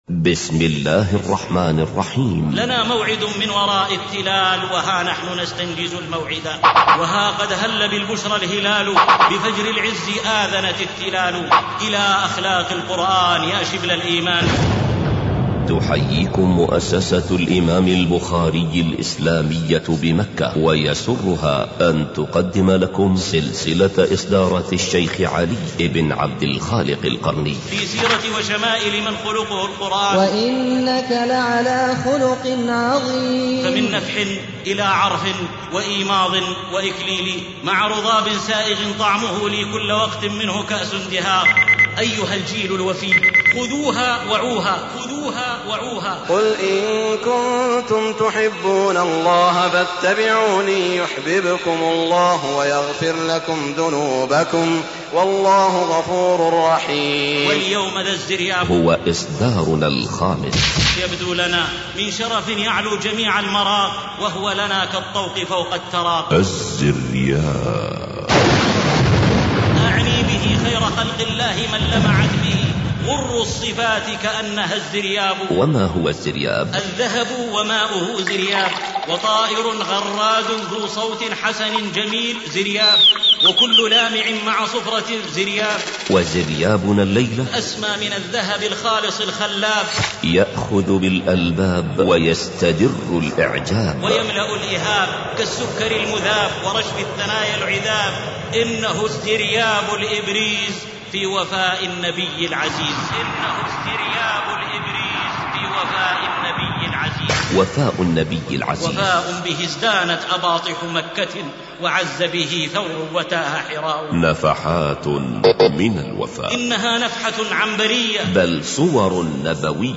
خطب ومحاضرات